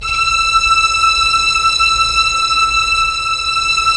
Index of /90_sSampleCDs/Roland - String Master Series/STR_Vlns 1 Symph/STR_Vls1 Symph